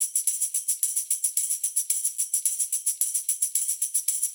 Index of /musicradar/sampled-funk-soul-samples/110bpm/Beats